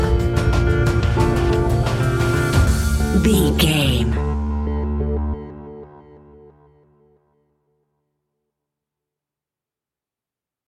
royalty free music
In-crescendo
Thriller
Aeolian/Minor
D
scary
ominous
dark
haunting
eerie
synthesiser
drums
percussion
ticking
electronic music